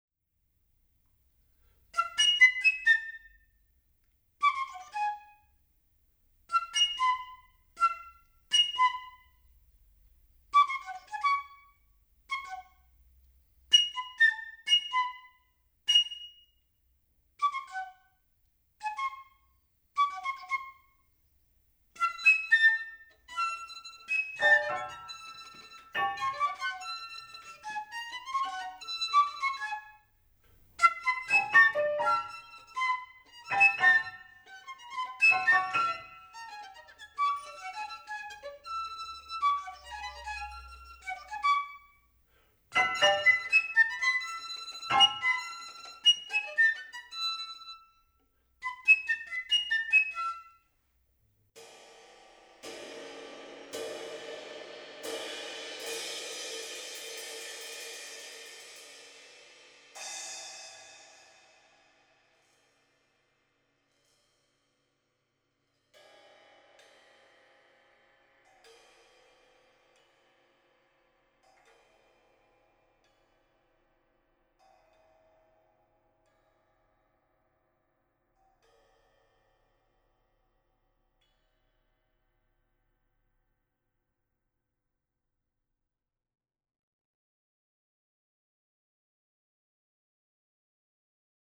Musica per balletto
Per voce recitante e canto
flauto e ottavino
arpa
oboe
violino
pianoforte e clavicembalo
chitarra e percussioni